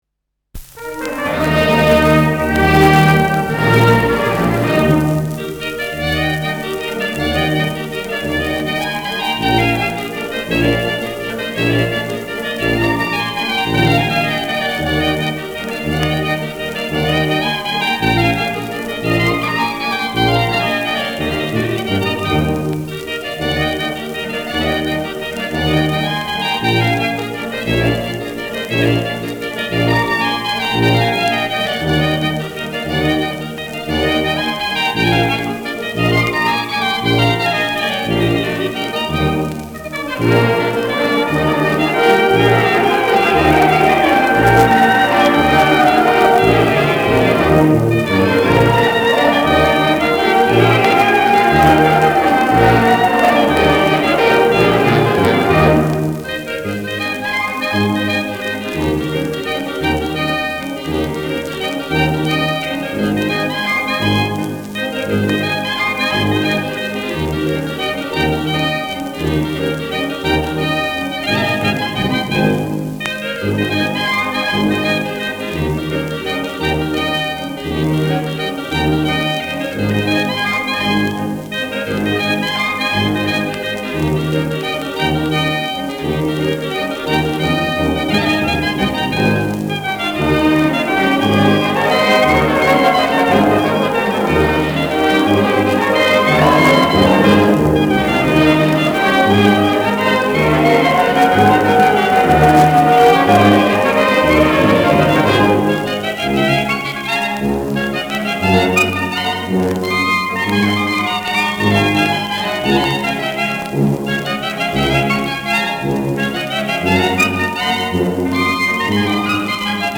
Schellackplatte
präsentes Knistern : leichtes Rauschen : leichtes Leiern
Große Besetzung mit viel Hall, die einen „symphonischen Klang“ erzeugt. Mit Juchzern.